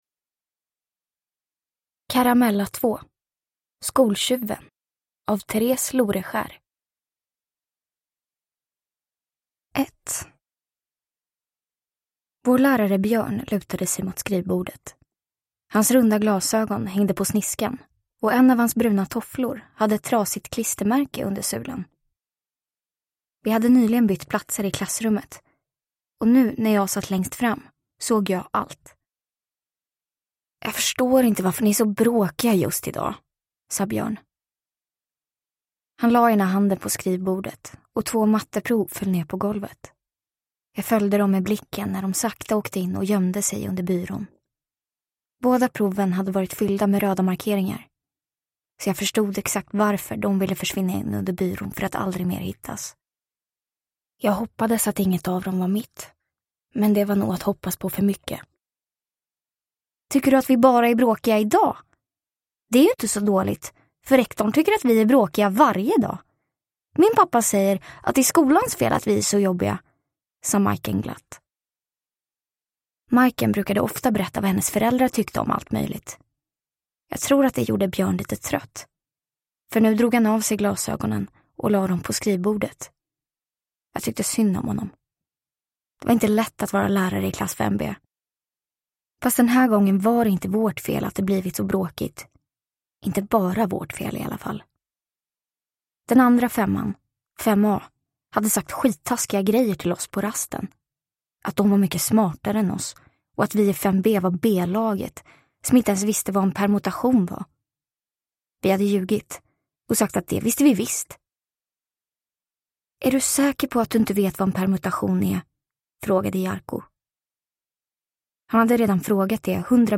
Skoltjuven – Ljudbok